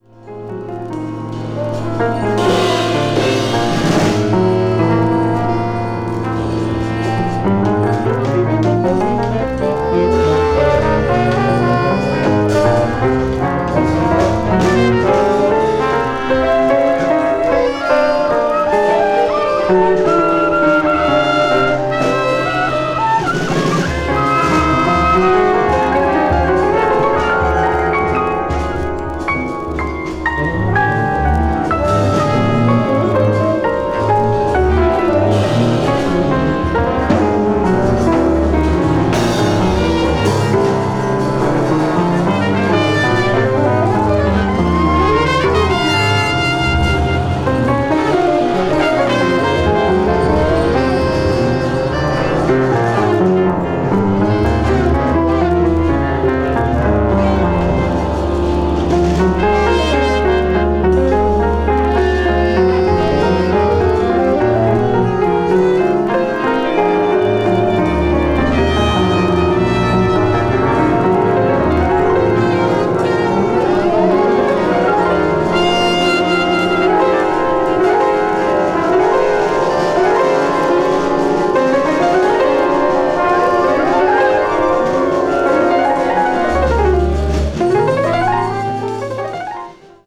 avant-jazz   free improvisation   free jazz   spiritual jazz